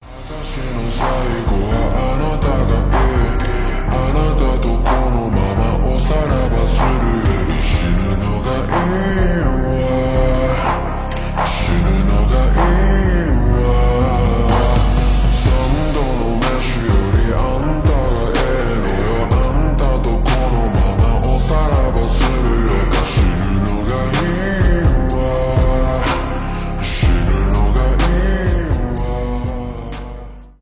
медленные , ремиксы
японские
поп